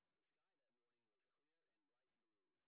sp10_street_snr30.wav